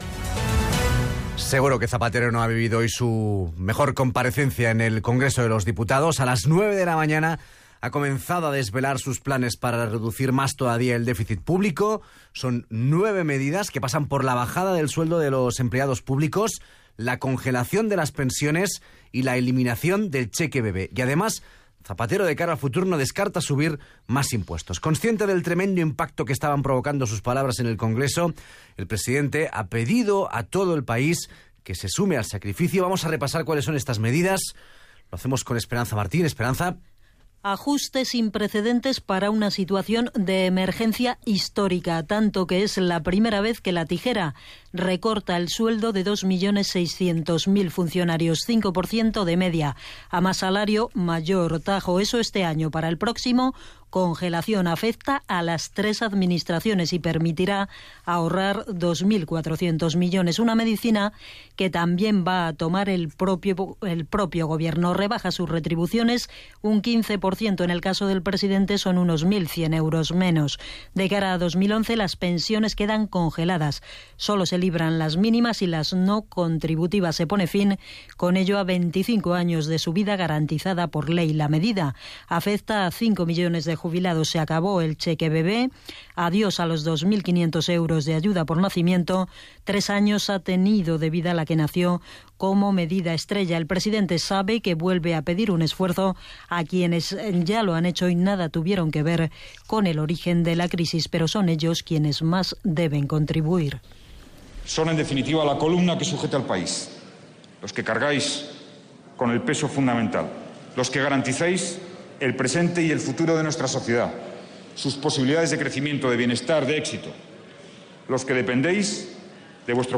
El president del govern espanyol ,José Luis Rodríguez Zapatero, anuncia nou mesures per retallar el pressupost públic al Parlament espanyol.
Informatiu